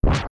even deeper swing sound than the tempered form.